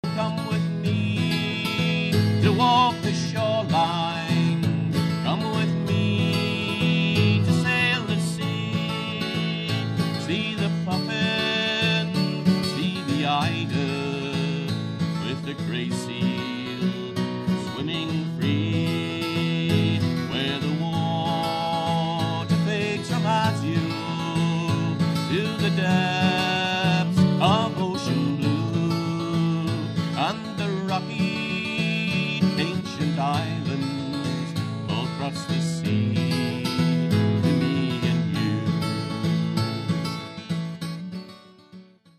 Ashington Folk Club - Spotlight 21 July 2005